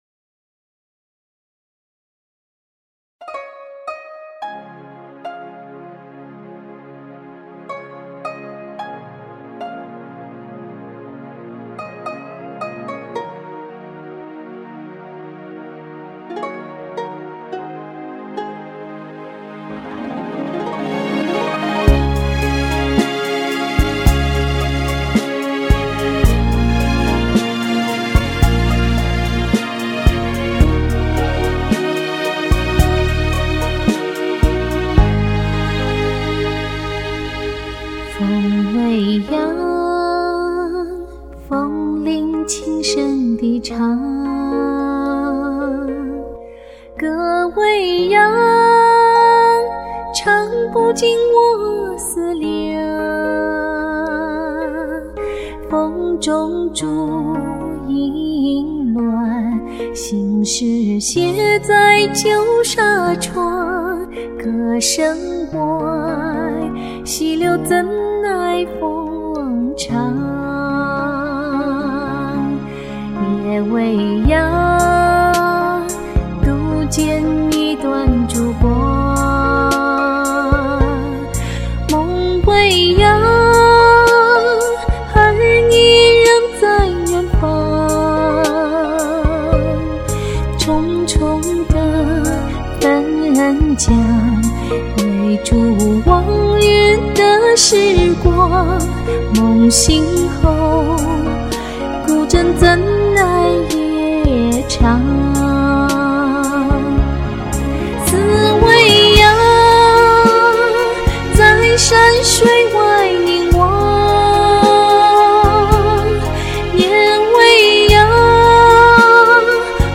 第一次听这首歌就特别喜欢，那种切切思念，淡淡忧伤，特别动人心弦。